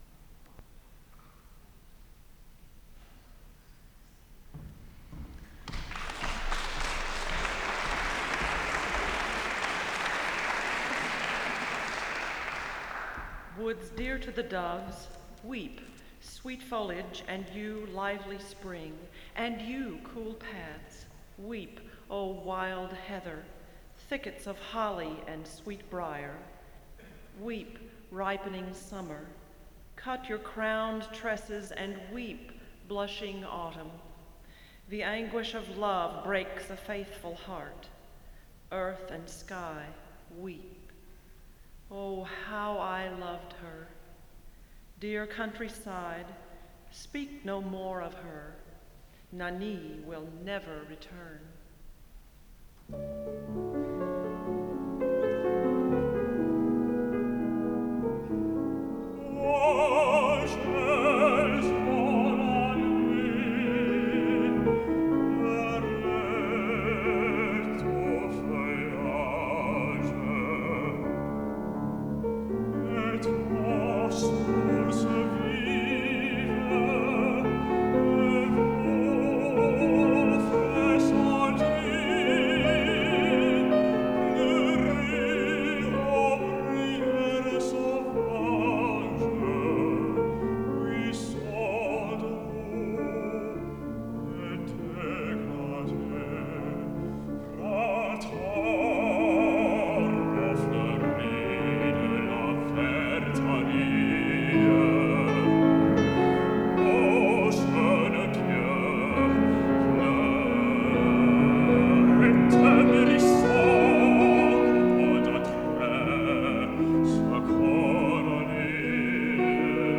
A CONCERT OF SONGS AND ARIAS featuring undergraduate voice students of The Shepherd School of Music Saturday, February 9, 2002 8:00 p.m. Stude Concert Hall